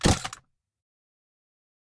DropGun.ogg